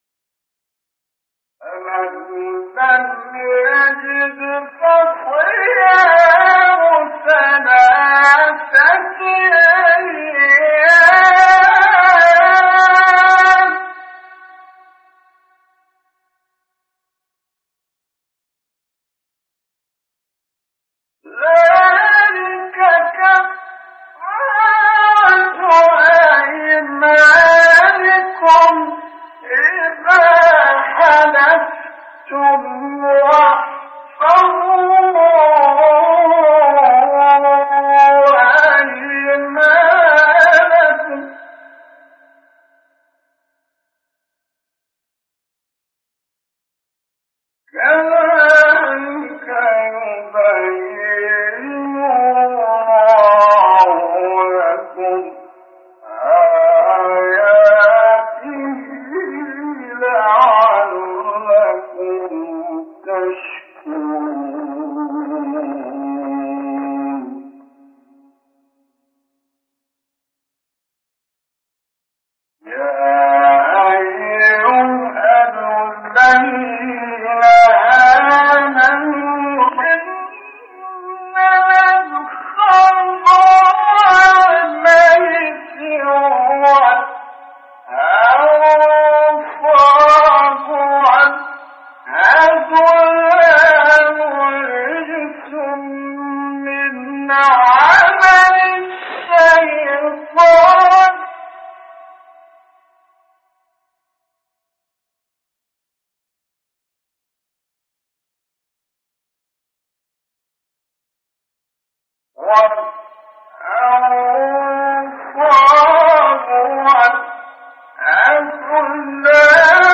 سوره : مائده آیه: 89-91 استاد : محمد رفعت مقام : بیات قبلی بعدی